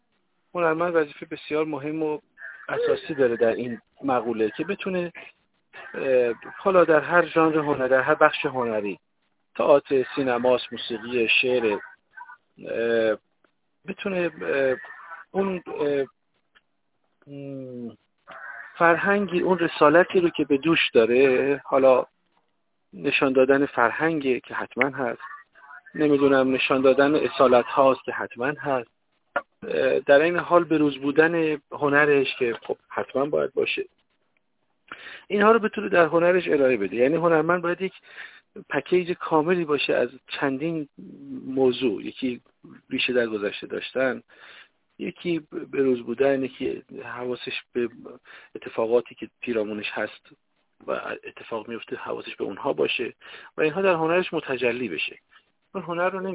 وحید تاج در گفت‌وگو با ایکنا: